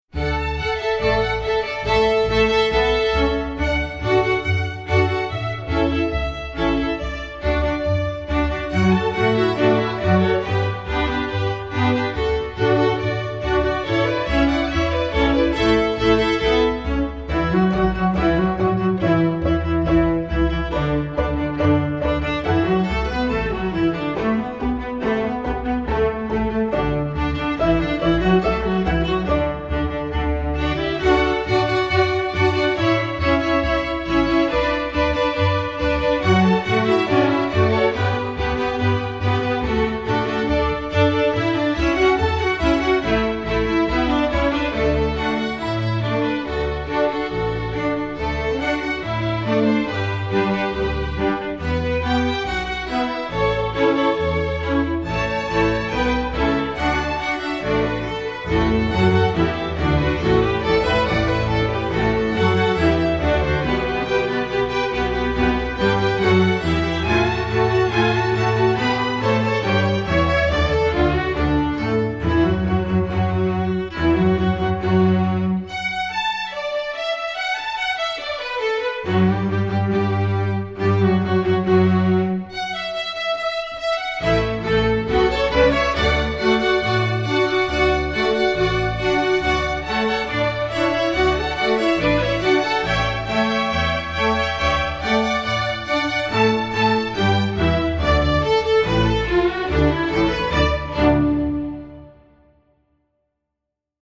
Composer: Traditional
Voicing: String Orchestra